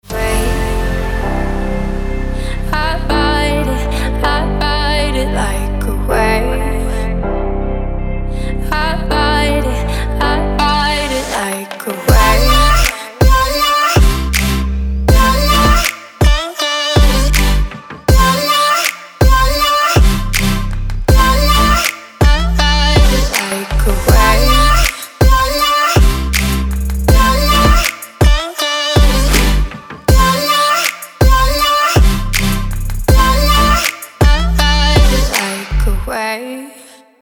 • Качество: 320, Stereo
женский вокал
dance
Electronic
future bass